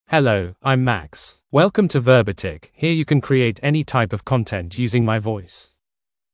MaleEnglish (United Kingdom)
MaxMale English AI voice
Max is a male AI voice for English (United Kingdom).
Voice sample
Listen to Max's male English voice.
Max delivers clear pronunciation with authentic United Kingdom English intonation, making your content sound professionally produced.